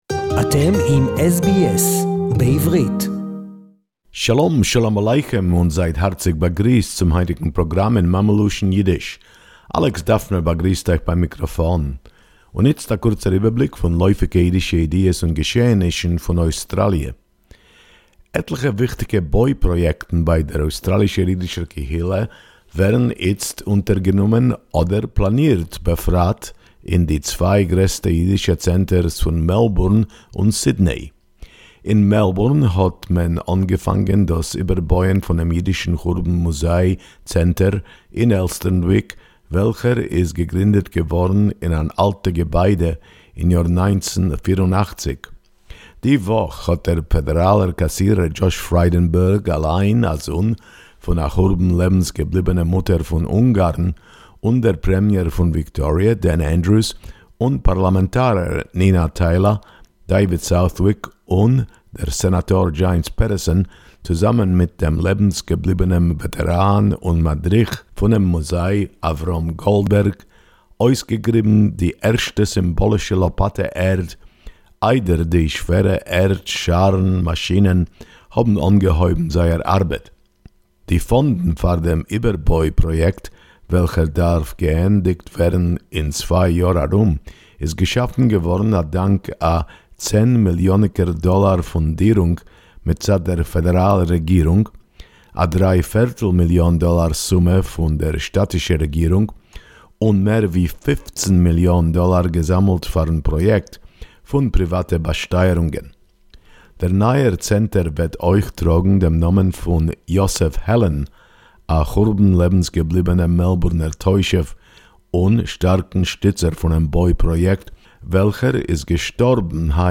Plans to relocate Mt Scopus College, the biggest Jewish school in Australia Yiddish report